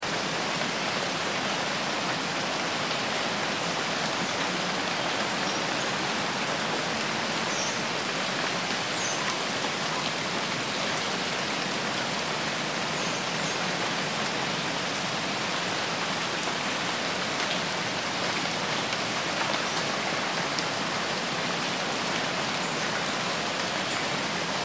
دانلود آهنگ جنگل 9 از افکت صوتی طبیعت و محیط
جلوه های صوتی
دانلود صدای جنگل 9 از ساعد نیوز با لینک مستقیم و کیفیت بالا